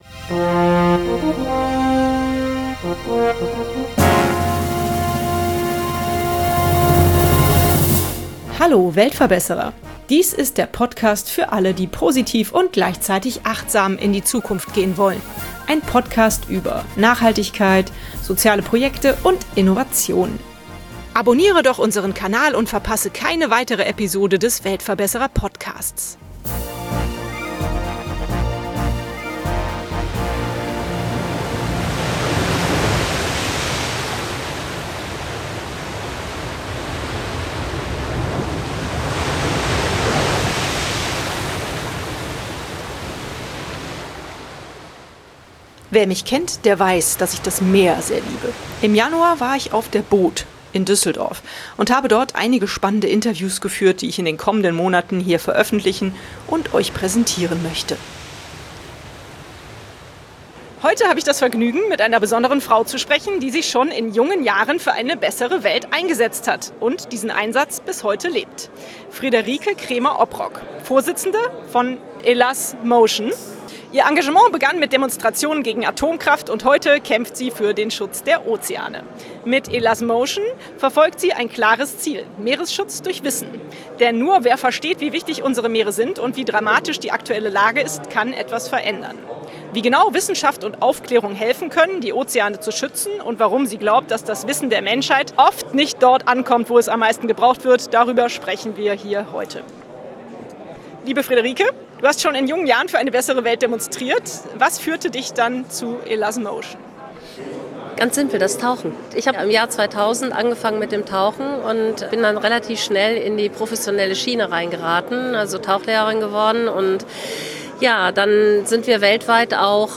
Heute habe ich das Vergnügen, mit einer Frau zu sprechen, die sich schon in jungen Jahren für eine bessere Welt eingesetzt hat und diesen Einsatz bis heute lebt: